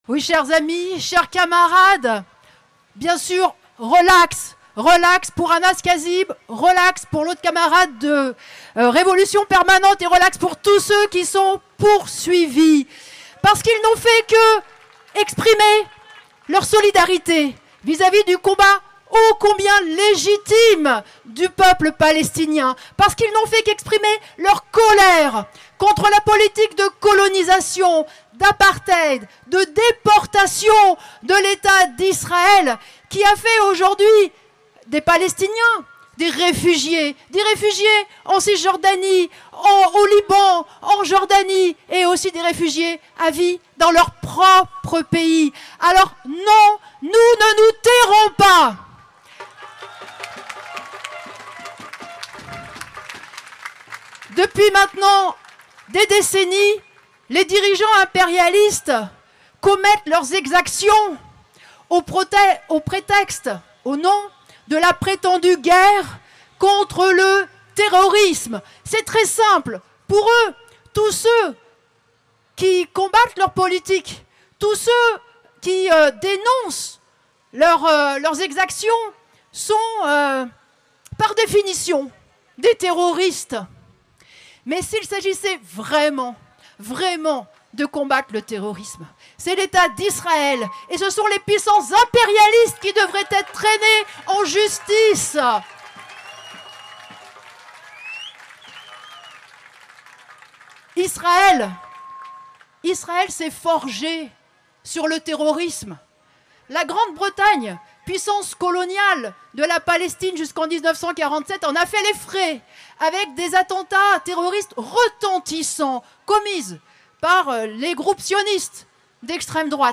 Rassemblement de soutien aux militants de Révolution permanente attaqués en justice : Intervention de Nathalie Arthaud